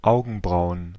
Ääntäminen
Ääntäminen Tuntematon aksentti: IPA: /ˈaʊ̯ɡn̩ˌbʀaʊ̯ən/ Haettu sana löytyi näillä lähdekielillä: saksa Käännöksiä ei löytynyt valitulle kohdekielelle. Augenbrauen on sanan Augenbraue monikko.